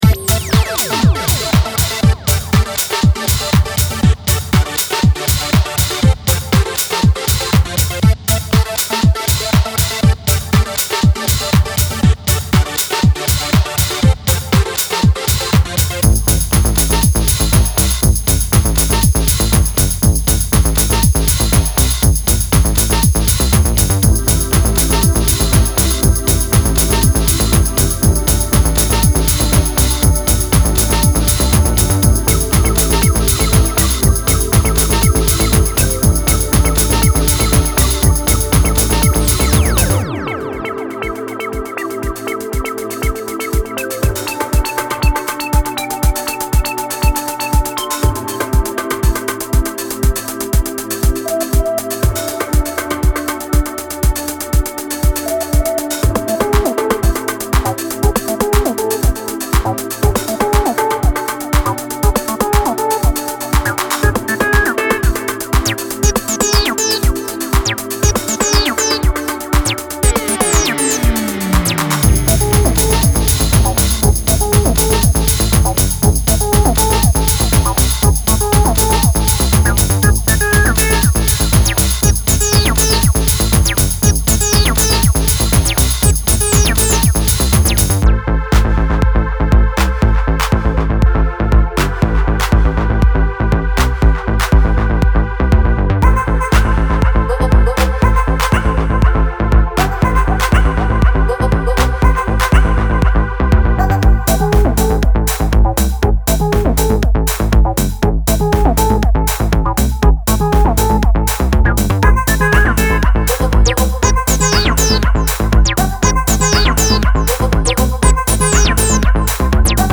Genre:Techno
テンポは110から120 BPMまで幅広く、さまざまなスタイルに適用することができます。
デモサウンドはコチラ↓